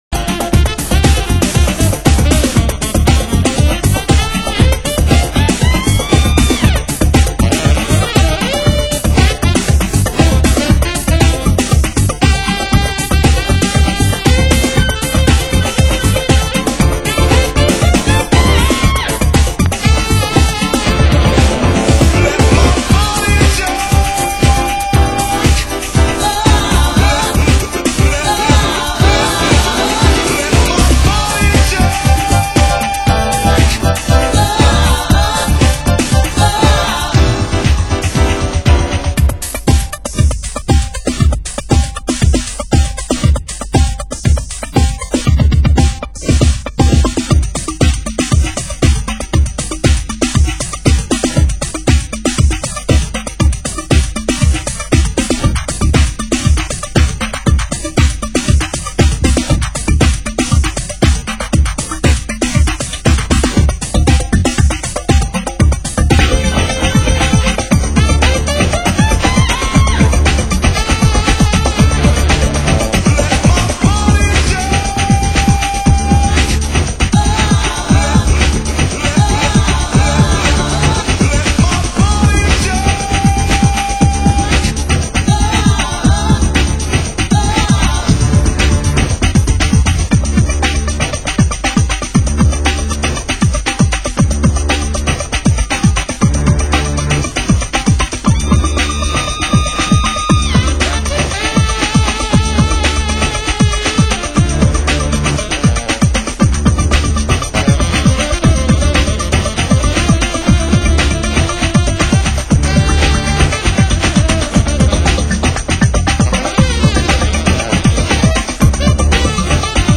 Genre: Warehouse